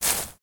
step_bush.ogg